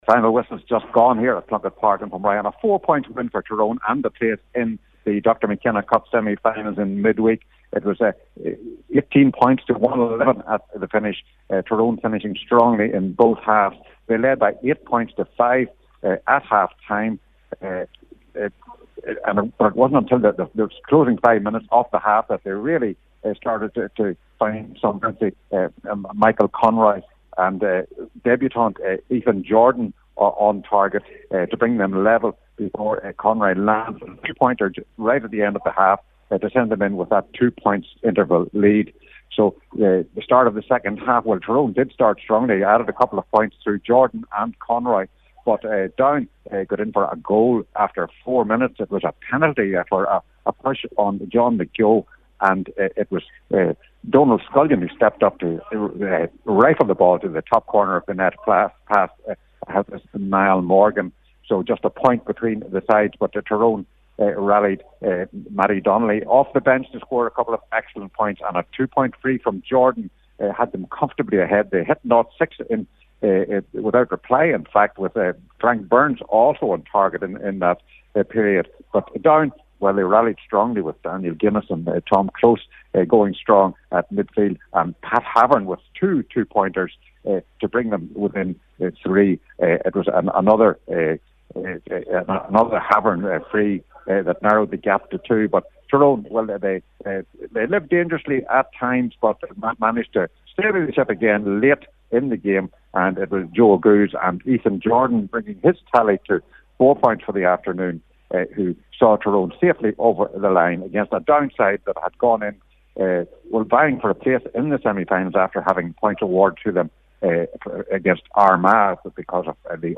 full time report